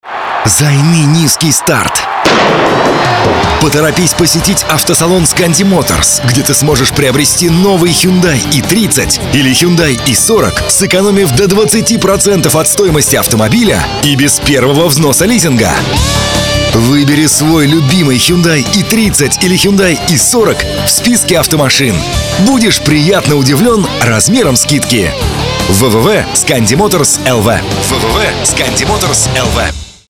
rusky_muzsky.mp3